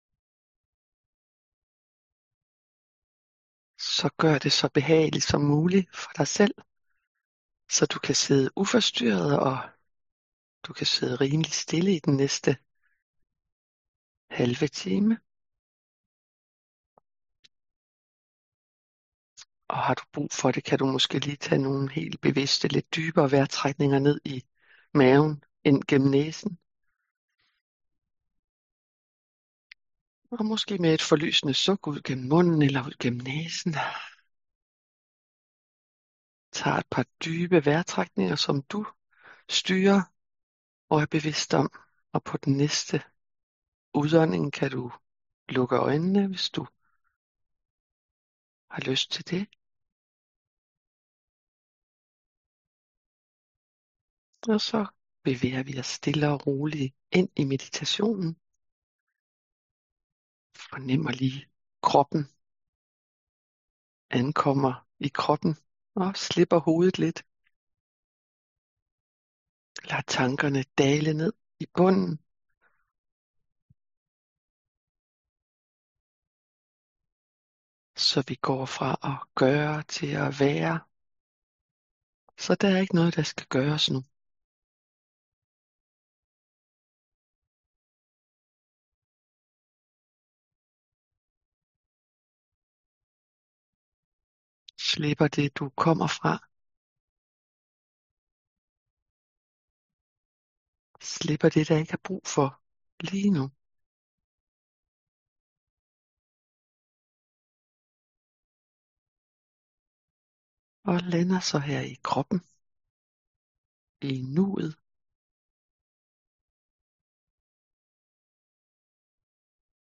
Her kan du lytte til og downloade Guidede Morgen Meditationer af Mindfulness Foreningens medlemmer.